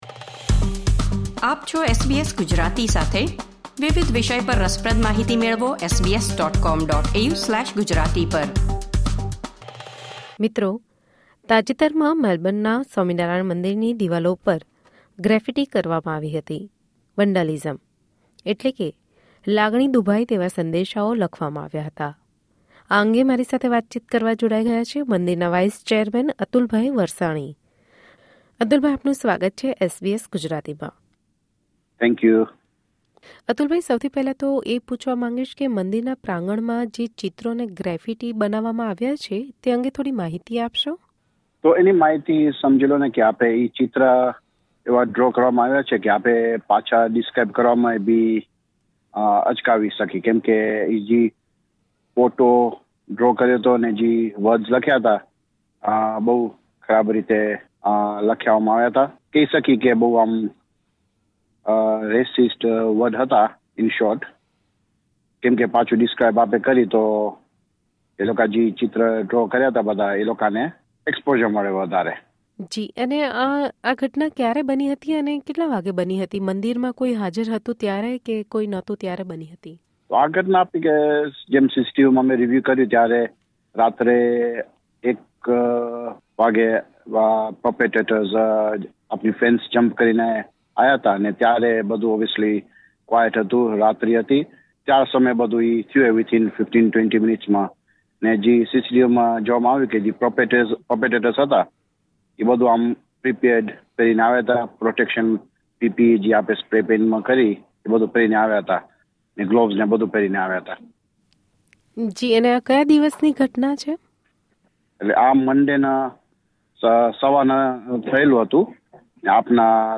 જાણો, સમગ્ર ઘટના વિશે ઓડિયો ઇન્ટરવ્યુ દ્વારા.